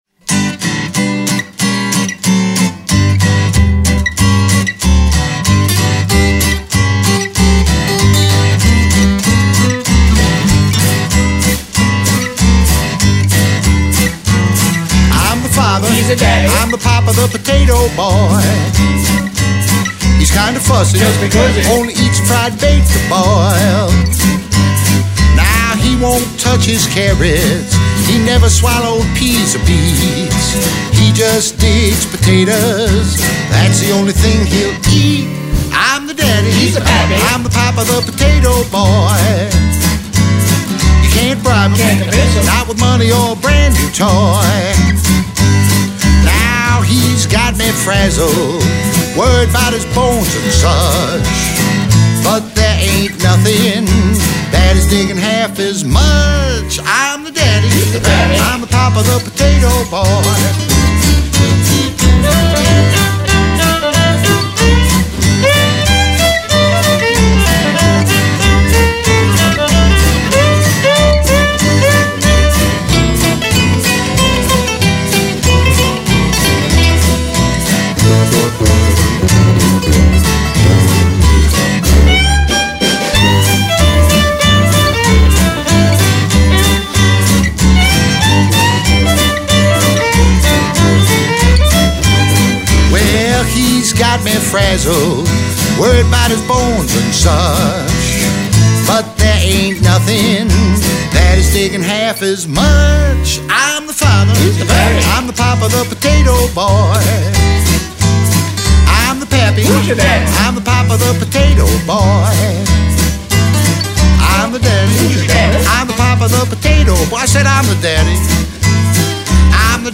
recorded at Levon Helm Studios in Woodstock